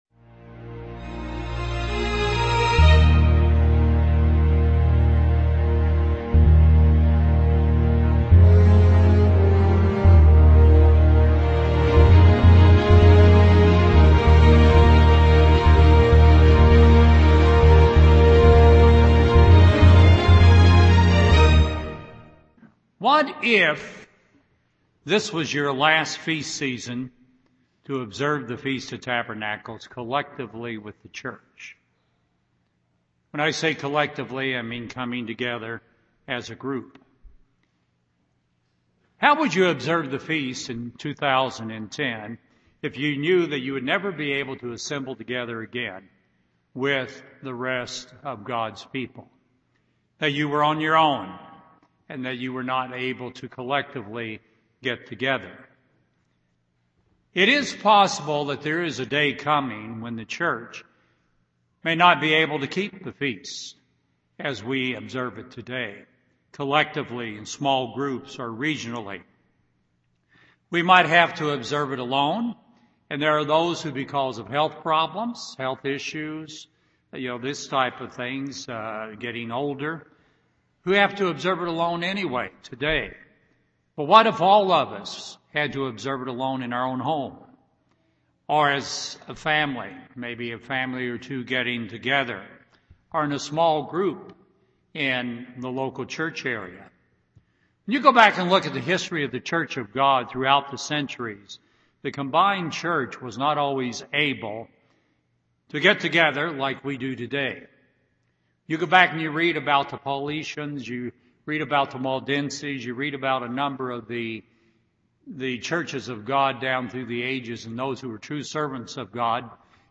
Matthew 20:25-28 UCG Sermon Transcript This transcript was generated by AI and may contain errors.